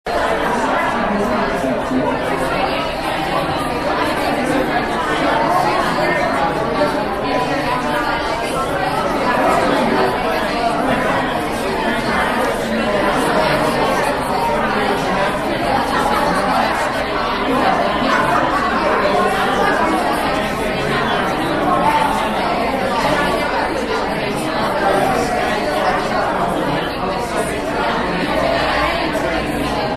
North Central NAMA Mtg. - Speed NetworkingAfter we worked through the online version we moved to a face to face speed networking session.
Basically, everyone sits around tables and when I say “go” they introduce themselves to the person across the table and get to know each other.
nama-speed-networking.mp3